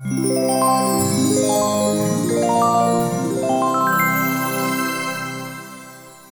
Звуки волшебной палочки
Звон волшебного кольца в момент исполнения желания